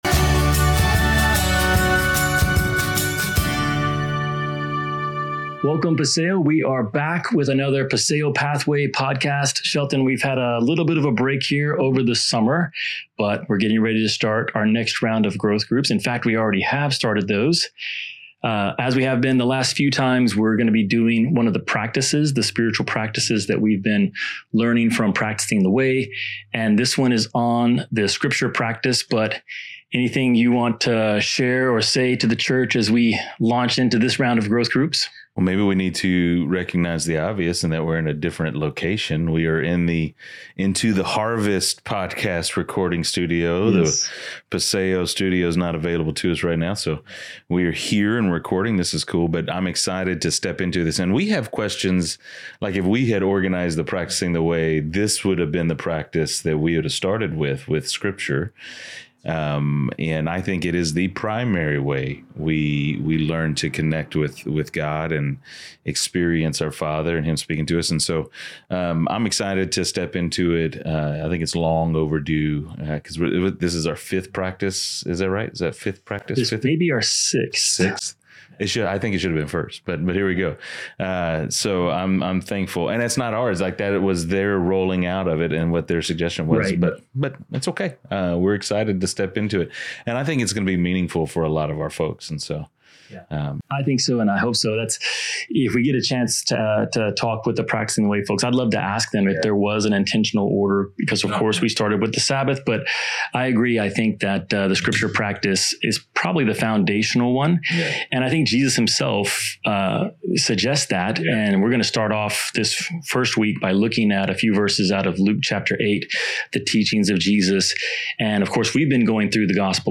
Not simply as a box to check, but as a way to meet with Jesus, our Teacher, and be changed by Him. Whether you’ve been a Christian for years or you’re just starting out, this conversation will help you read in a way that leads to transformation.